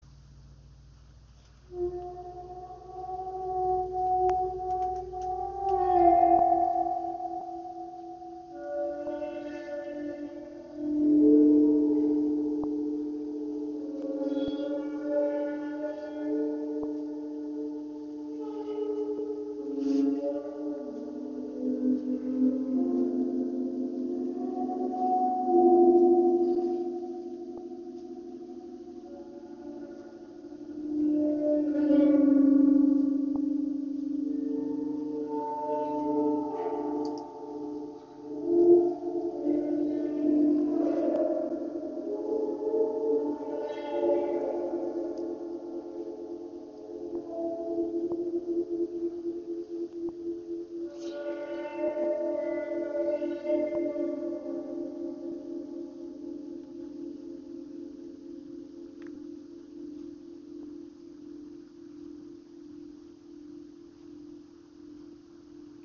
Mit einem WOM Gong erhältst du ein vielschichtiges und harmonisches Klangspektrum. Weiche Bässe bilden die Grundlage, auf der sich mehrstimmige Chöre entfalten.